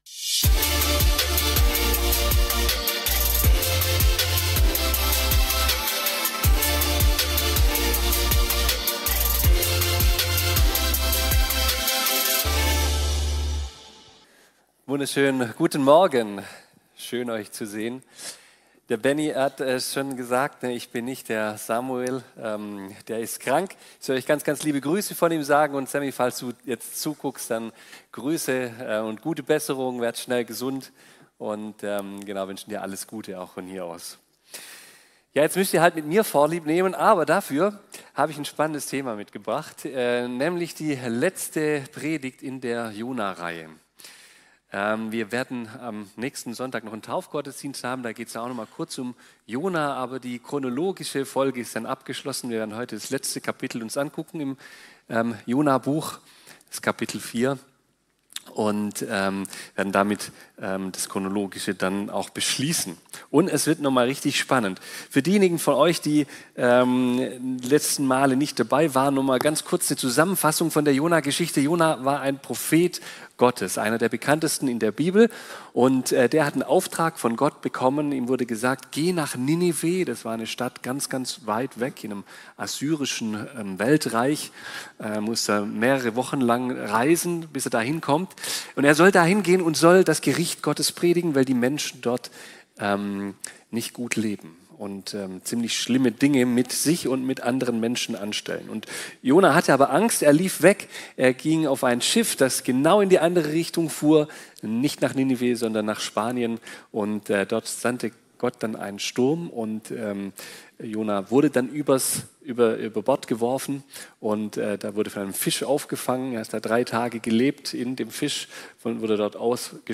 Predigten der FeG Lörrach